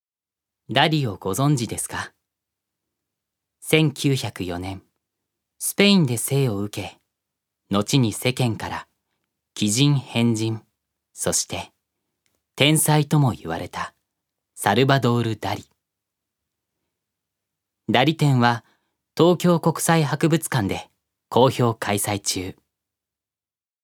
預かり：男性
ナレーション２